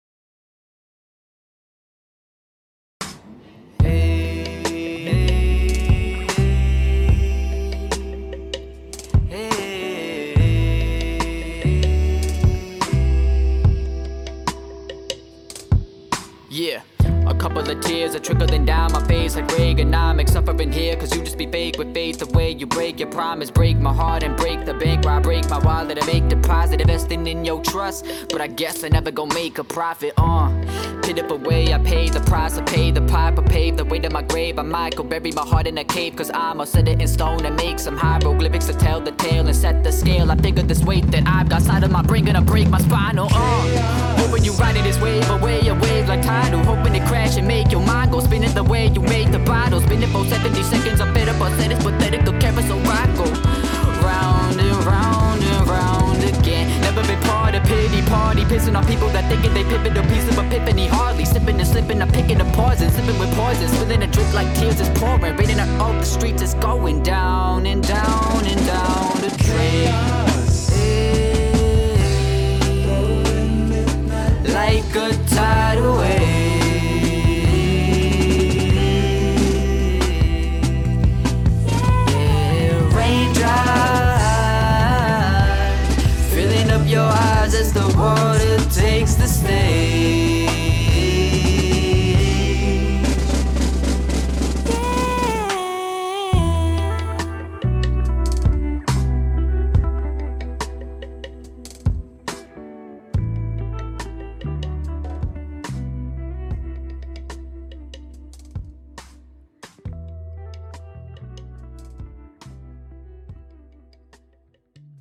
دسته : نیوایج